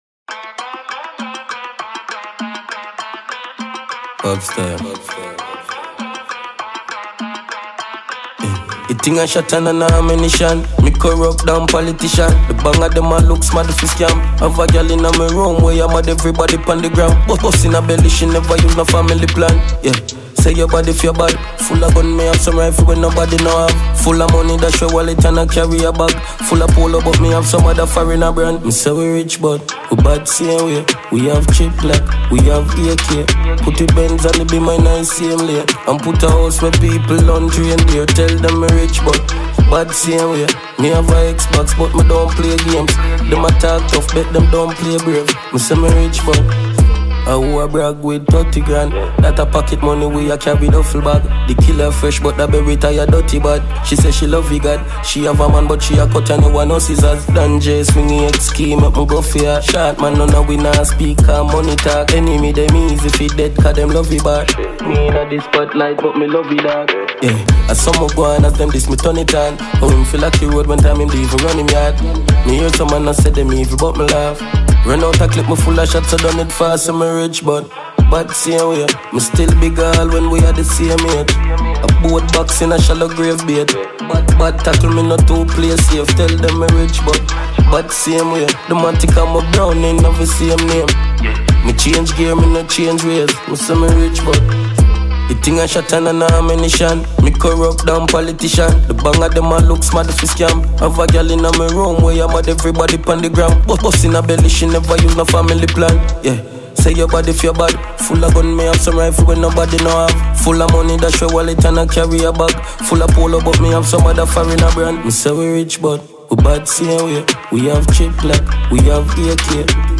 Dancehall Music